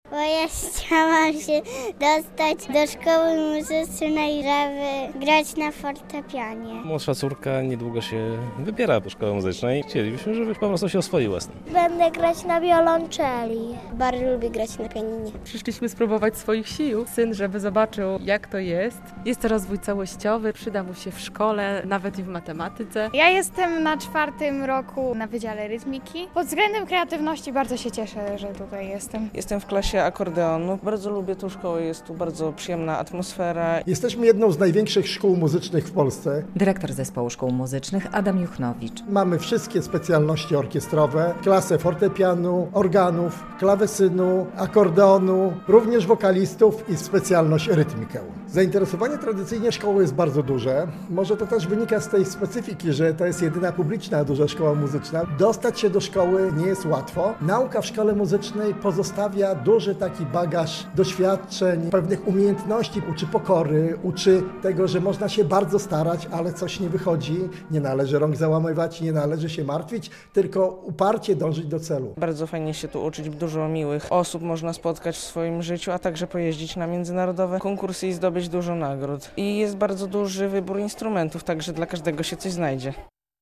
Setki osób odwiedzają w sobotę (07.05) Zespół Szkół Muzycznych w Białymstoku.